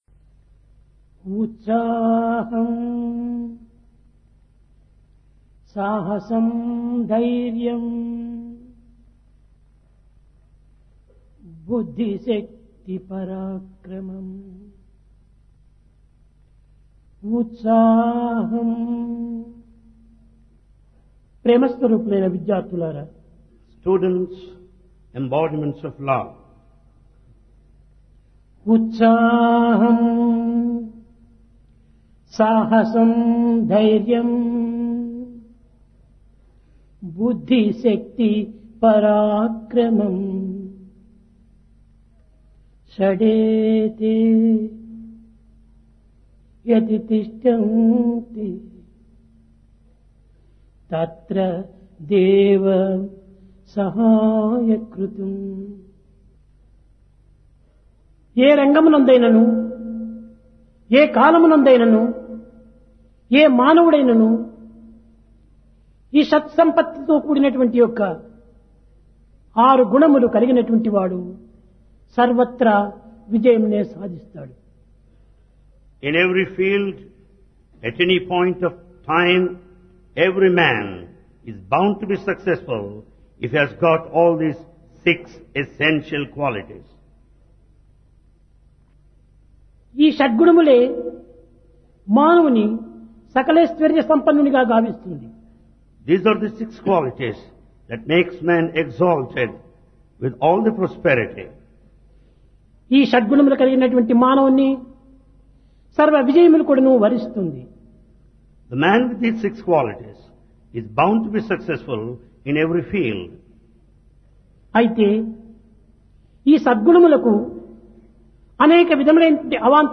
Divine Discourse of Bhagawan Sri Sathya Sai Baba
Place Prasanthi Nilayam Occasion Sankranthi, Sports Meet